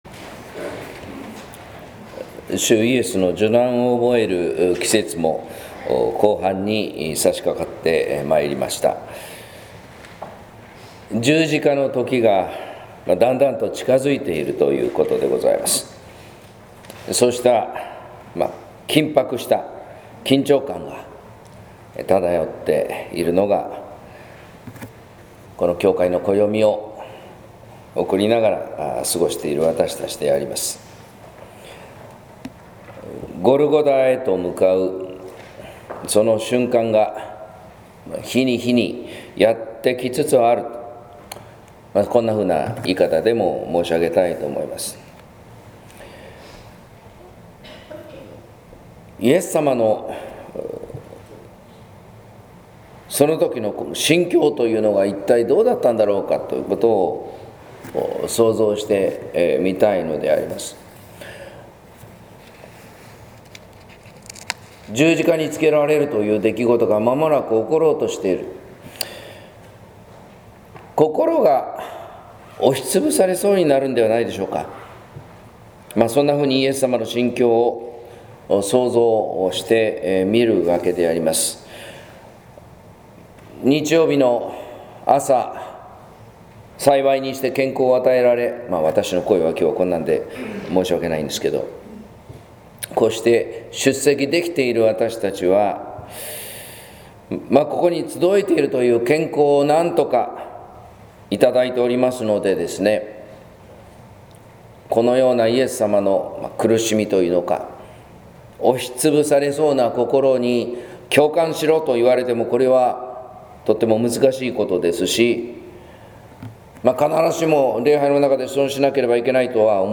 説教「自分のいのちを生きない」（音声版）